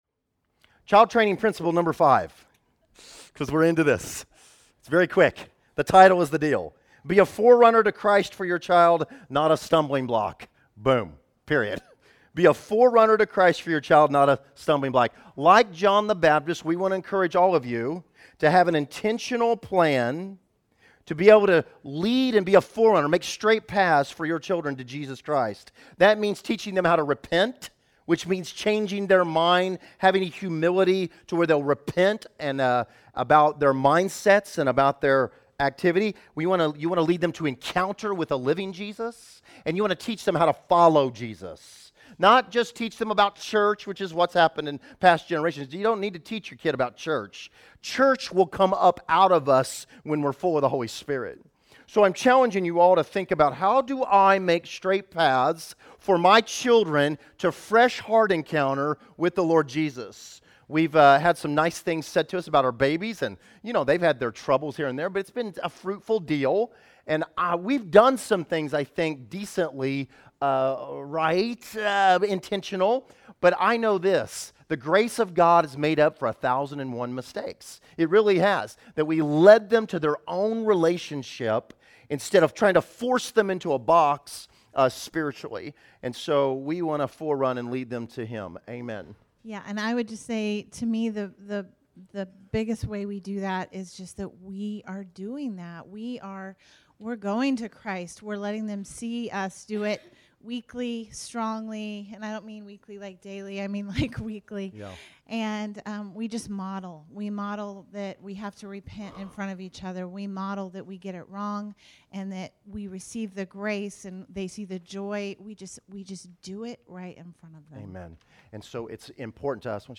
Category: Teachings